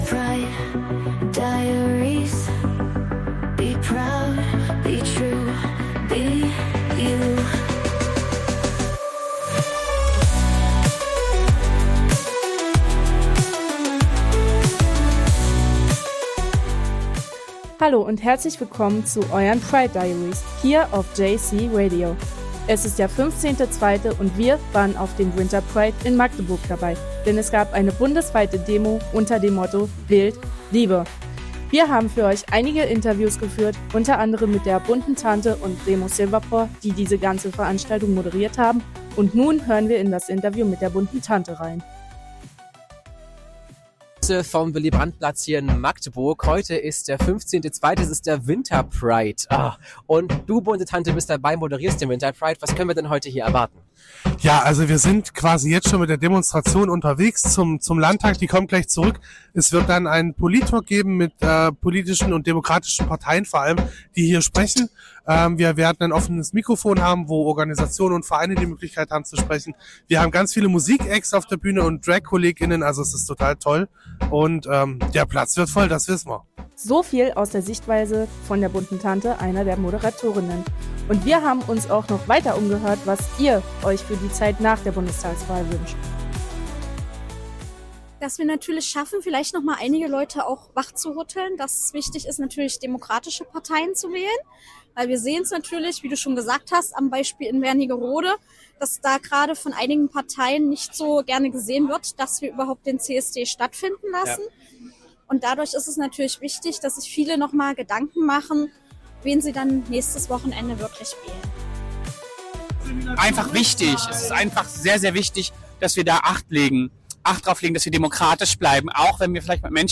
Dieser Zusammenschnitt unseres Besuchen vom Winter Pride 2025 in